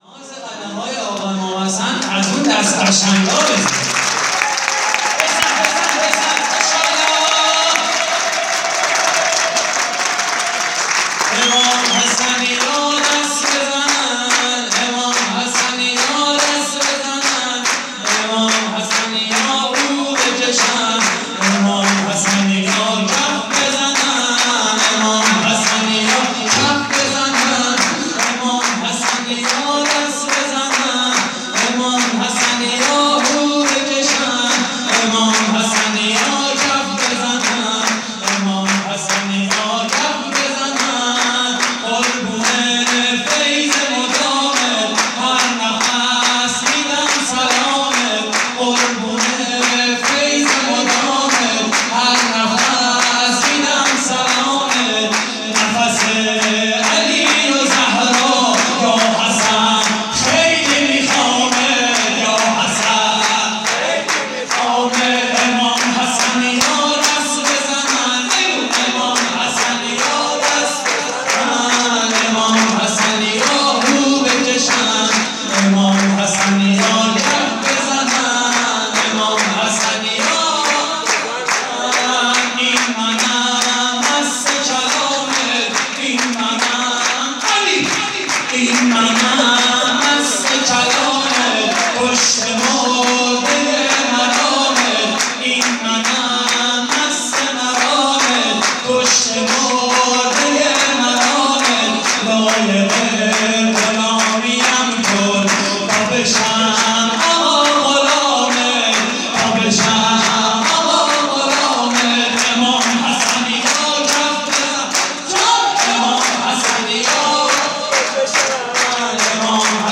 مراسم جشن ولادت امام حسن مجتبی (ع) / هیئت رایة الهدی – دانشگاه علوم پزشکی حضرت بقیة الله (عج)؛ 28 فروردین 1401
شور: امام حسنیا دست بزنند؛ پخش آنلاین |